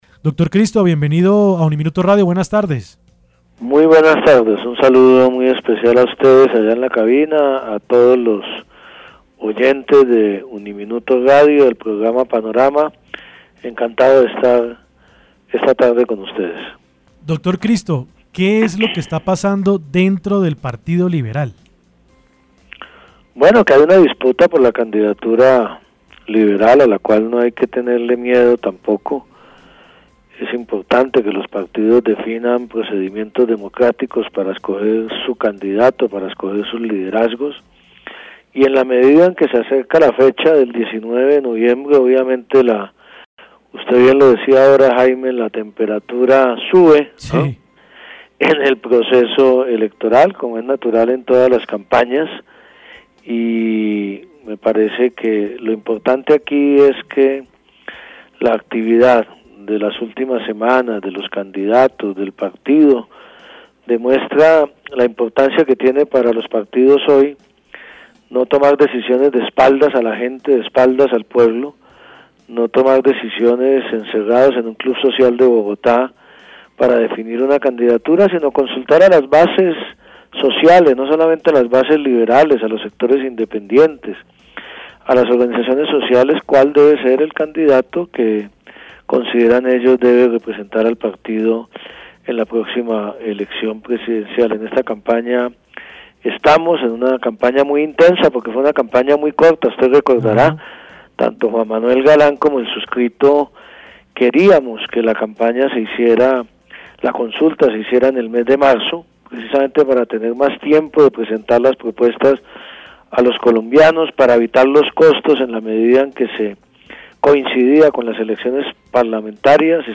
En diálogo con UNIMINUTO Radio estuvo el precandidato presidencial Juan Fernando Cristo quien criticó a varios integrantes del partido Liberal porque, según él, hay favoritismo por Humberto De la…
Entrevista-a-Juan-Fernando-Cristo-precandidato-presidencial.mp3